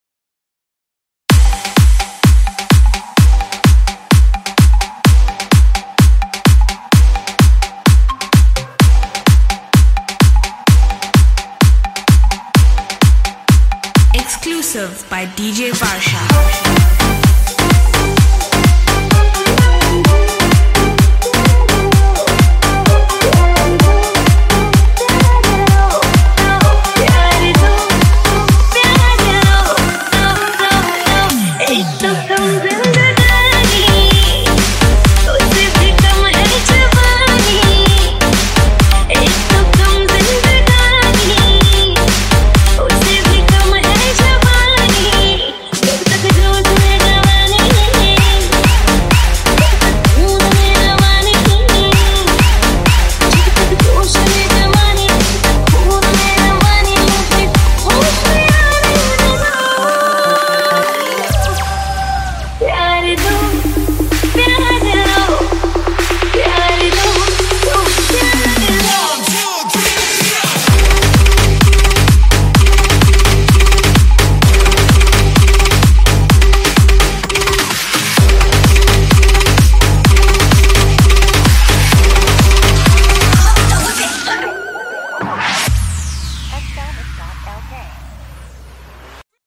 High quality Sri Lankan remix MP3 (1).